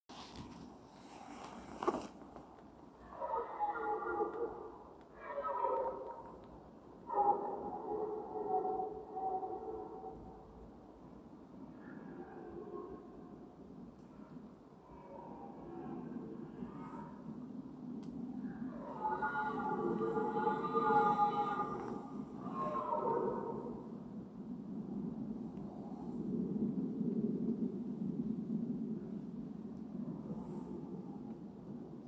Camping De Pollen, Emst op de Veluwe
burlen van de bronstige herten en het huilen van wolven (?!?) in de verte.
Burlen.mp3